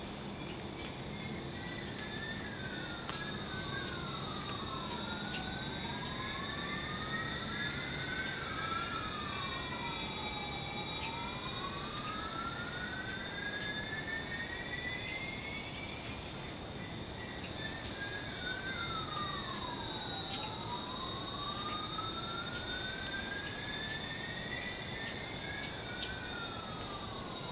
Other species, like the siren cicada in Singapore, have a more tonal call which occupies only a small percentage of the acoustic space.
Siren cicadas saturate less of the acoustic space – notice all the white space in the spectrogram.